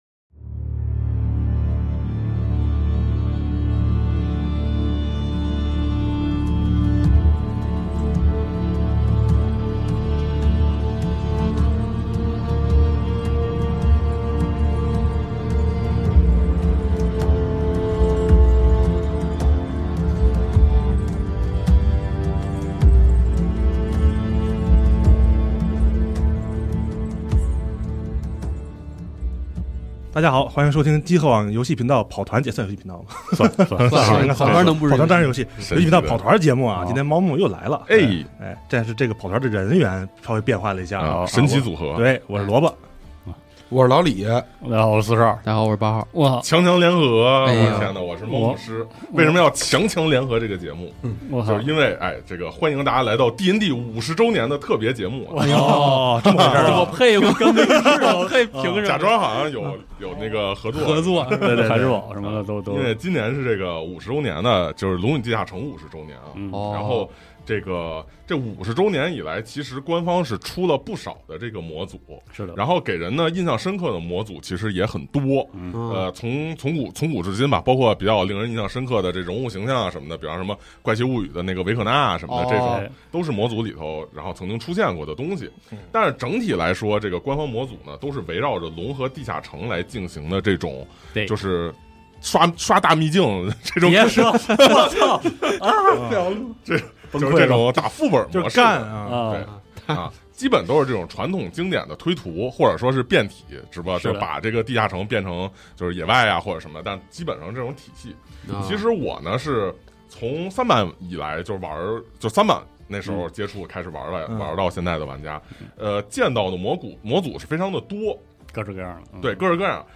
本节目是《龙与地下城》系列跑团节目，《龙枪编年史》世界观，DND5E规则下的一次桌面角色扮演游戏纪实录音。